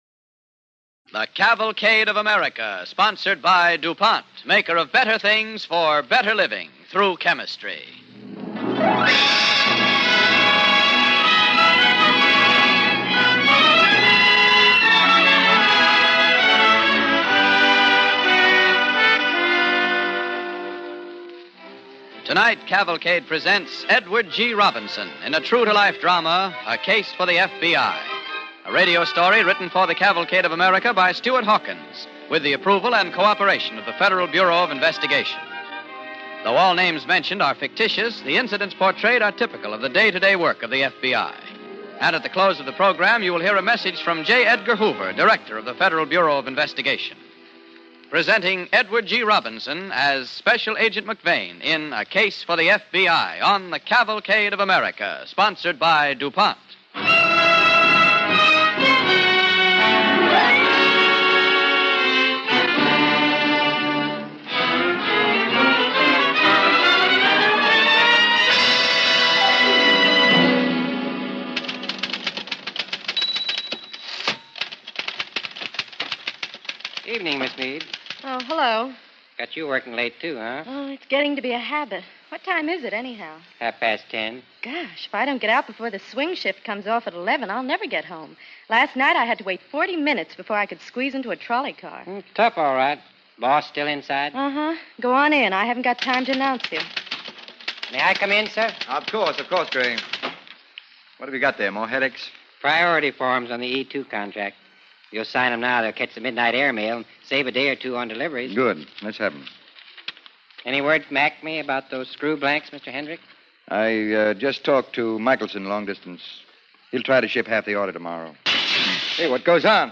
Cavalcade of America Radio Program A Case for the F.B.I., starring Edward G. Robinson and J. Edgar Hoover, March 15, 1943